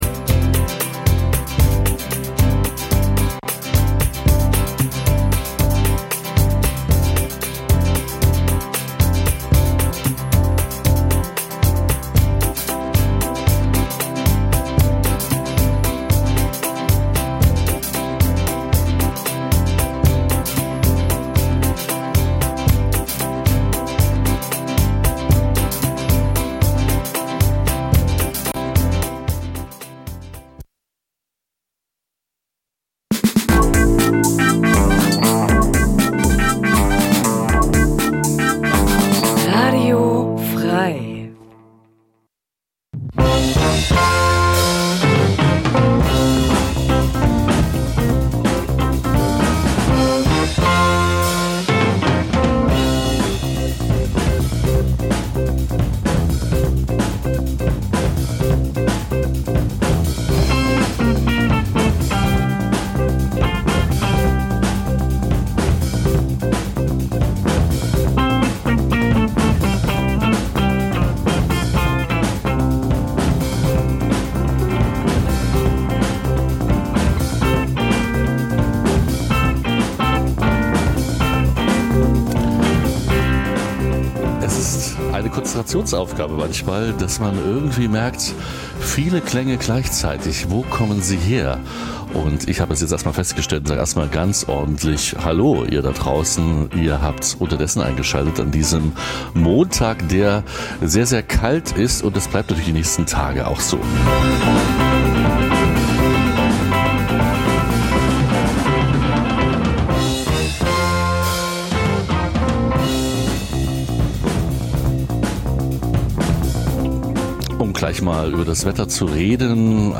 Das tagesaktuelle Livemagazin sendet ab 2024 montags bis freitags 9-11 Uhr. Jeden Tag von anderen Moderator:innen und thematisch abwechslungsreich best�ckt.